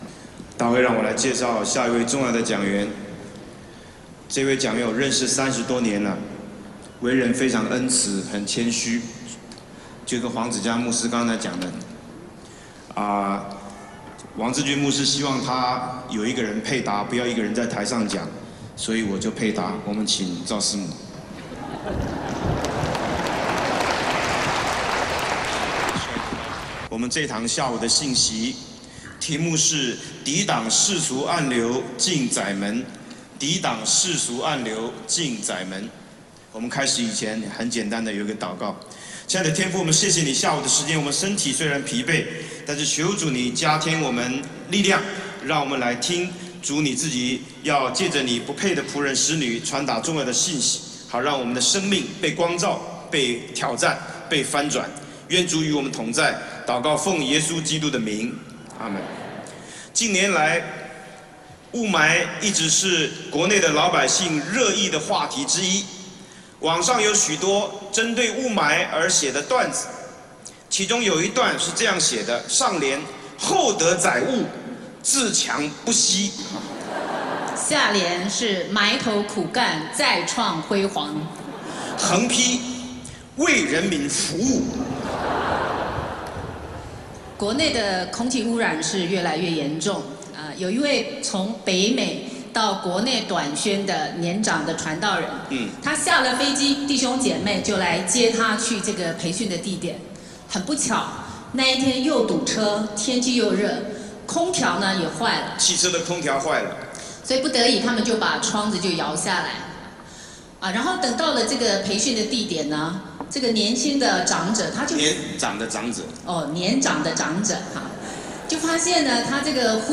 15福音大会